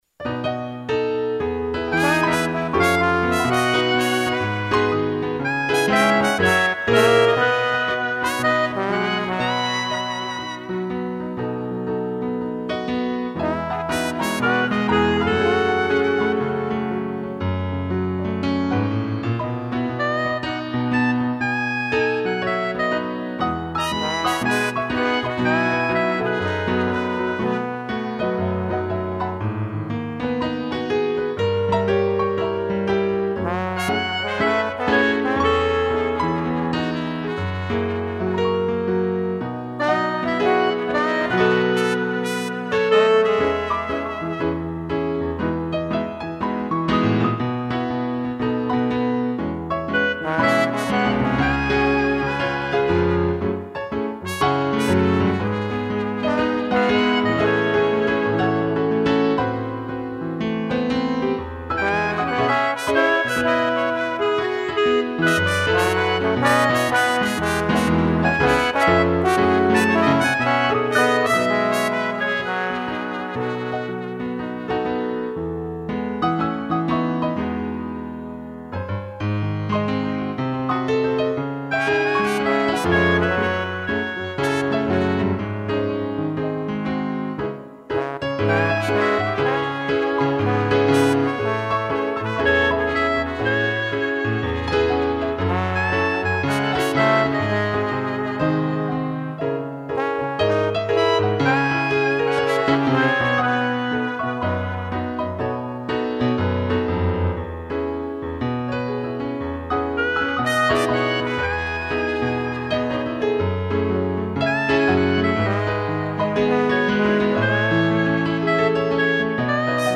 2 pianos e trombone
instrumental